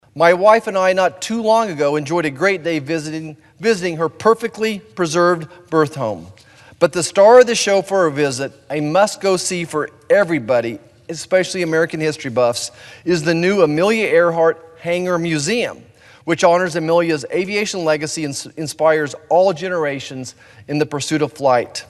Senator Roger Marshall praised not just Earhart but the city of Atchison on the Senate floor ahead of the statue’s placement earlier this week.